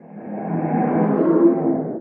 Minecraft Version Minecraft Version latest Latest Release | Latest Snapshot latest / assets / minecraft / sounds / entity / guardian / ambient2.ogg Compare With Compare With Latest Release | Latest Snapshot